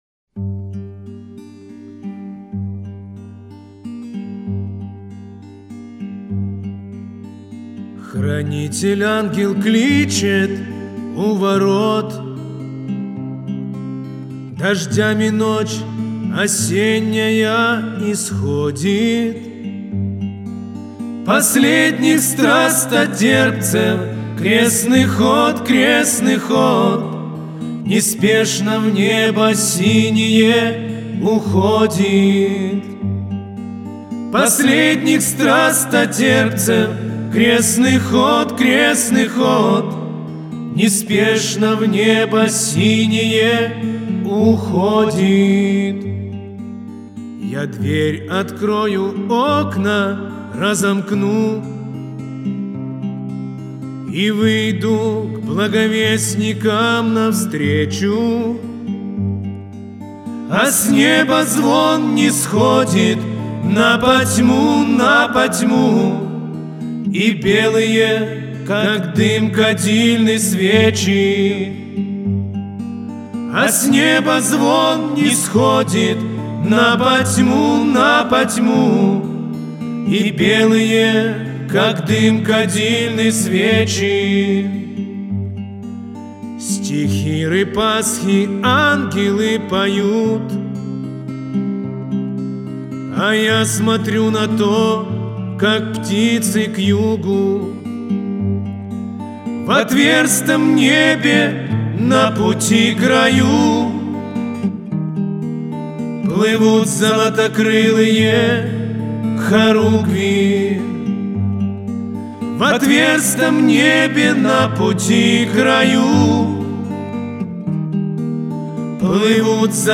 Православная музыка